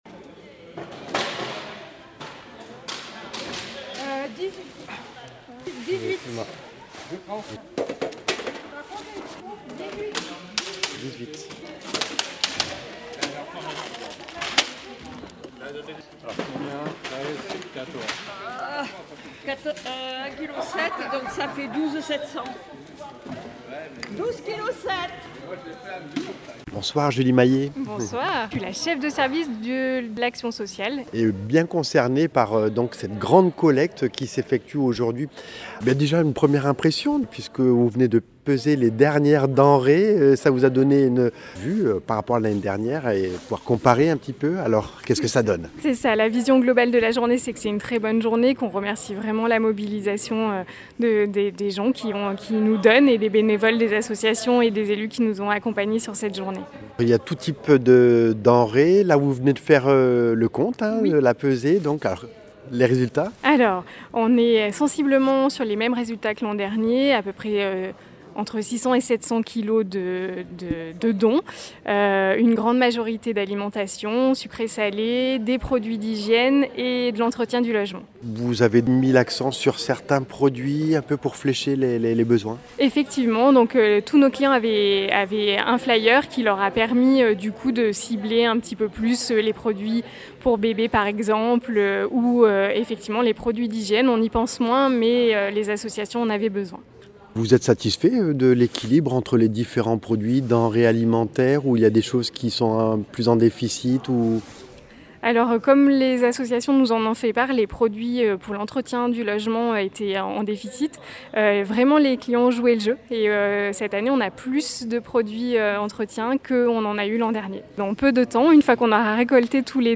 Reportage à St Marcellin à l’occasion de la journée solidarité ce samedi 7 décembre 2024 par l’organisation du Centre communal d’action sociale de la ville (CCAS) associé à d’autres structures du territoire.